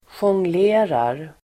Ladda ner uttalet
Uttal: [sjångl'e:rar el. j-]